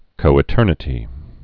(kōĭ-tûrnĭ-tē)